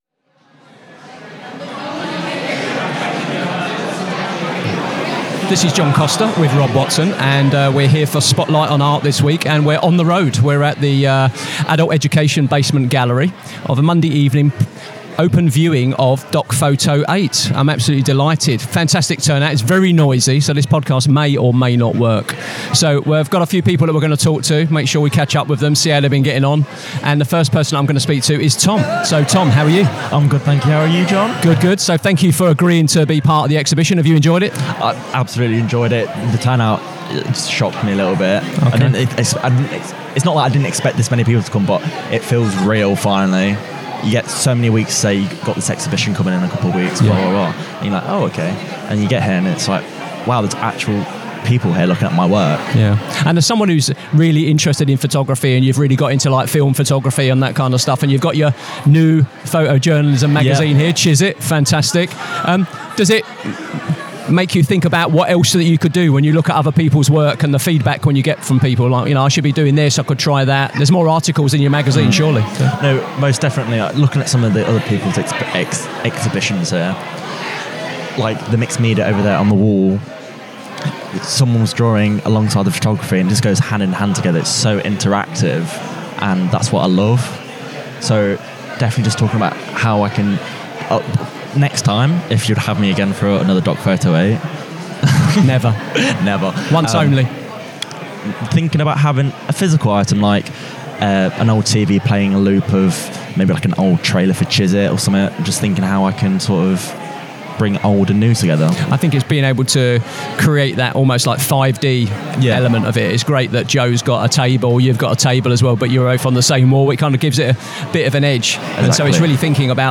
This week, Spotlight on Arts went on the road to capture the atmosphere and conversations from the open viewing of DocPhoto 8, held at the Basement Gallery of Leicester Adult Education College.
We spoke with a range of contributors—from first-time exhibitors to experienced photographers—each bringing their own voice, style, and motivations to the gallery walls. Whether capturing intimate portraits, experimenting with AI imagery, exploring social themes, or drawing inspiration from community heritage, each artist reflected on what photography means to them and how their practice has developed over time.